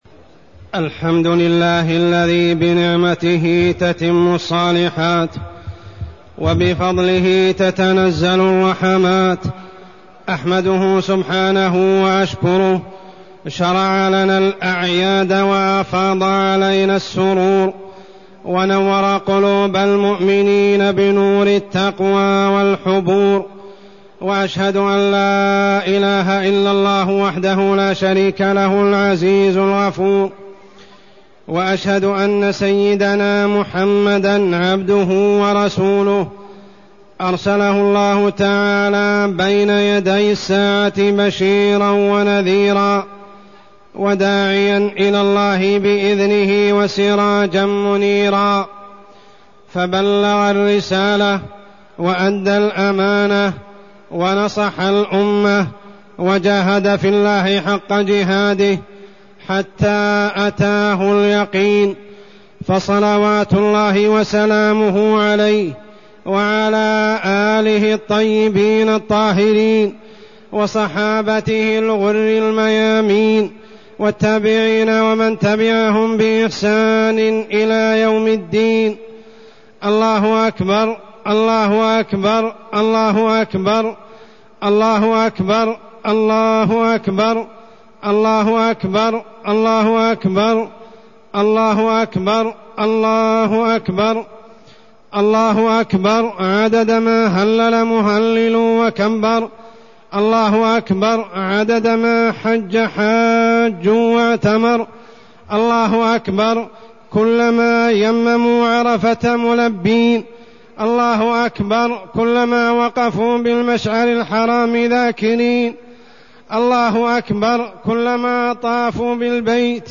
خطبة عيد الأضحى -يوم الحج الأكبر
تاريخ النشر ١٠ ذو الحجة ١٤٢١ هـ المكان: المسجد الحرام الشيخ: عمر السبيل عمر السبيل خطبة عيد الأضحى -يوم الحج الأكبر